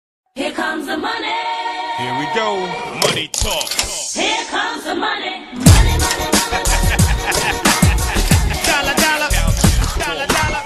here-comes-the-money-sound-effect_sBINrEC.mp3